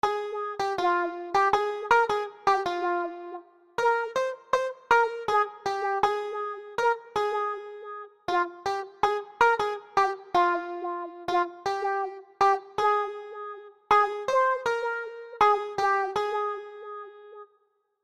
描述：吉他手
标签： 80 bpm Weird Loops Guitar Electric Loops 3.03 MB wav Key : Unknown
声道立体声